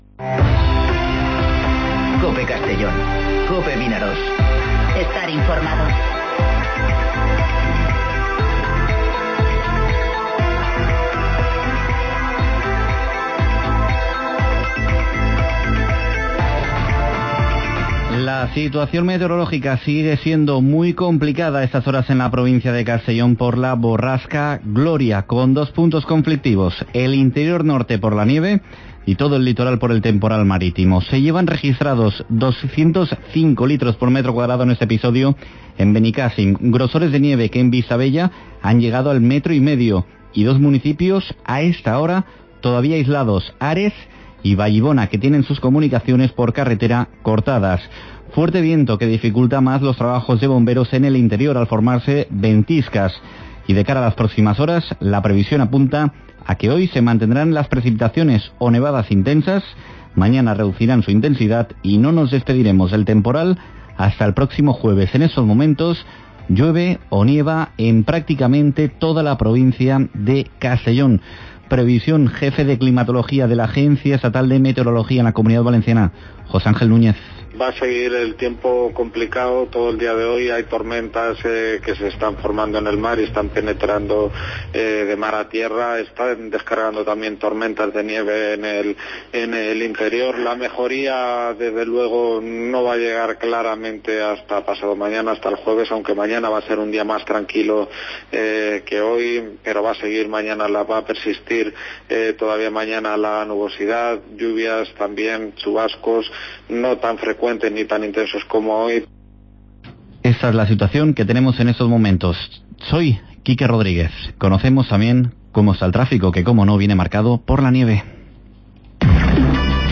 Informativo Mediodía COPE en Castellón (21/01/2020)